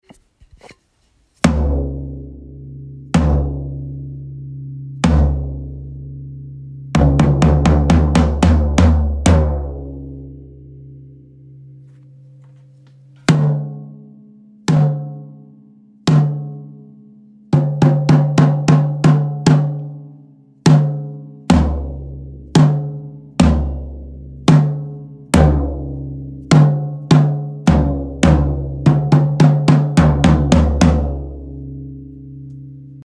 Die 13er klingt eigentlich gut, aber die 16er gefällt mir in der hohen Stimmlage überhaupt nicht. Auch habe ich auf beiden ein Summen, das mitschwingt bzw. Teile vom Set schwingen mit (Raum ist auch nur 17qm).
Anhänge Toms.mp3 649,9 KB